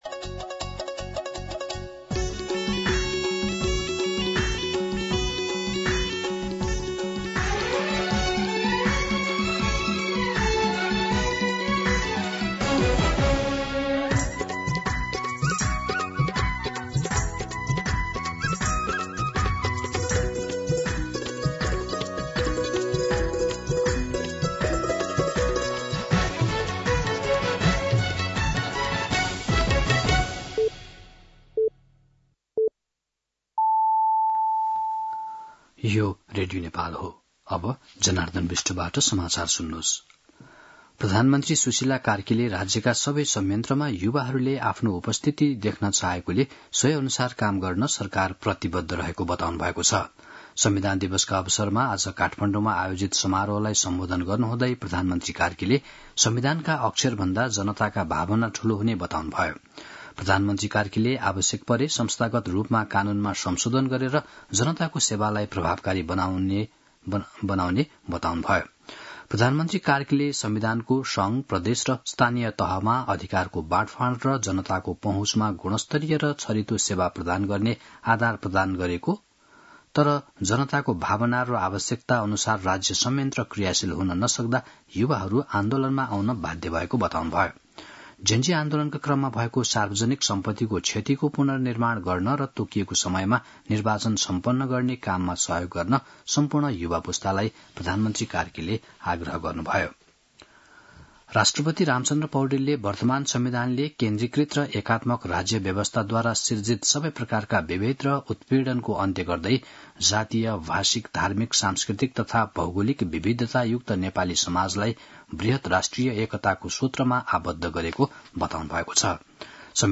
मध्यान्ह १२ बजेको नेपाली समाचार : ३ असोज , २०८२
12-pm-Nepali-News-1.mp3